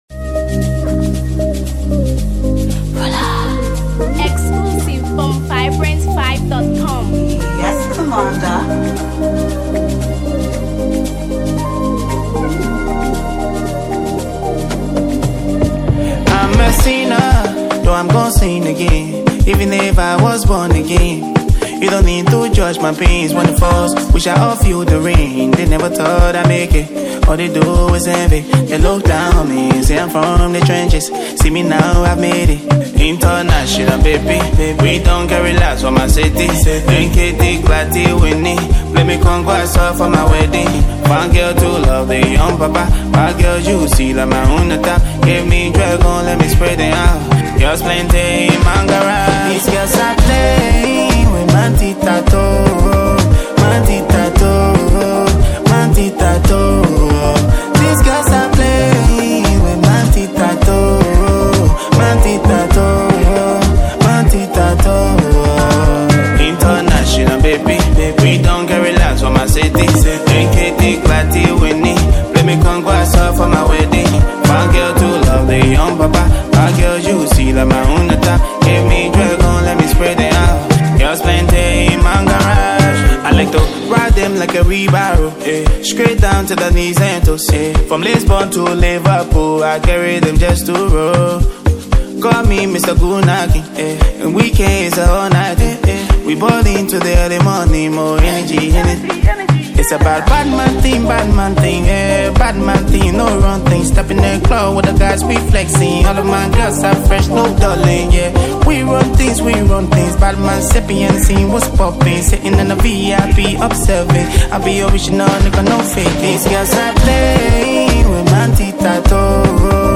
From the upbeat drums to the bright synths
feels effortlessly joyful
modern Liberian pop
catchy, confident, and unapologetically fun.